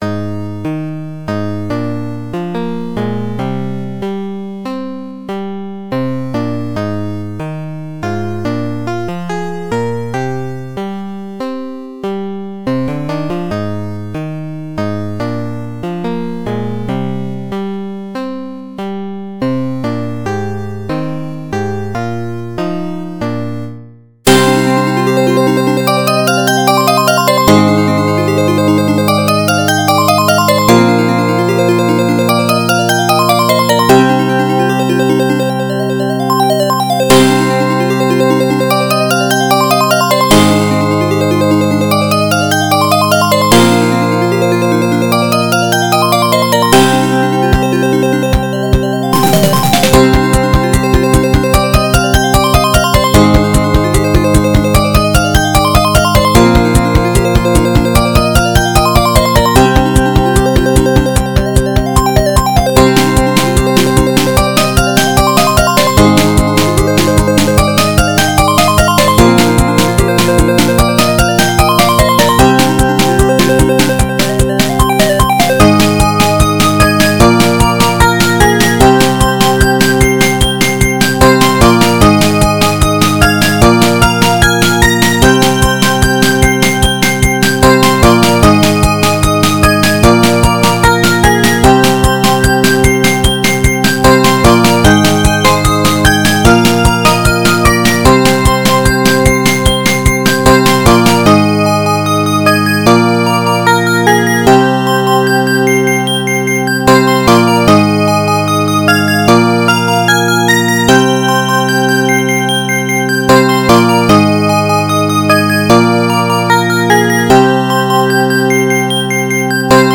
原游戏FM版，由PMDPlay导出。